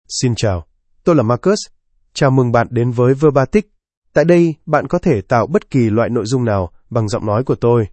Marcus — Male Vietnamese (Vietnam) AI Voice | TTS, Voice Cloning & Video | Verbatik AI
MarcusMale Vietnamese AI voice
Marcus is a male AI voice for Vietnamese (Vietnam).
Voice sample
Listen to Marcus's male Vietnamese voice.
Marcus delivers clear pronunciation with authentic Vietnam Vietnamese intonation, making your content sound professionally produced.